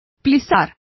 Complete with pronunciation of the translation of pleats.